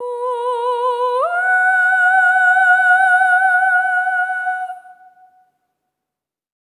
SOP5TH B4 -L.wav